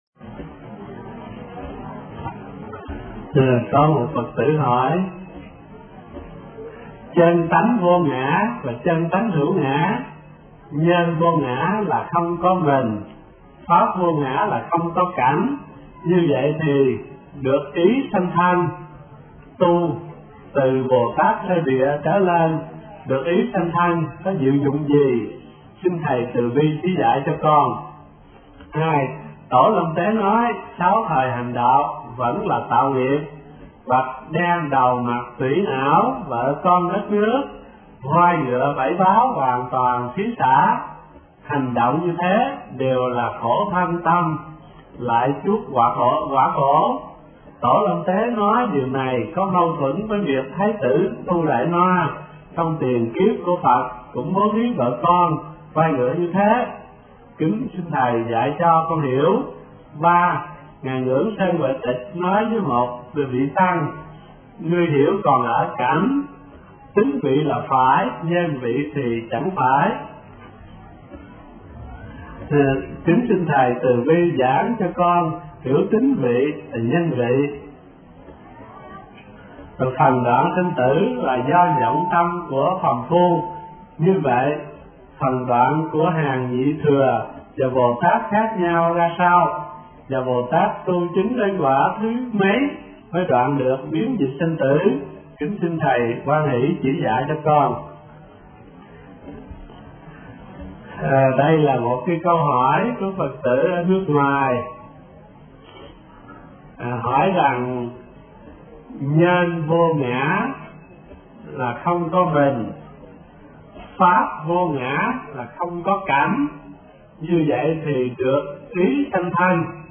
Đoạn Sanh Tử Của Bồ Tát – Tham vấn HT Thích Thanh Từ 54